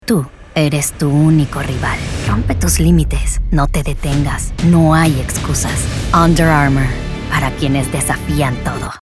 Commercial
Determined - Powerful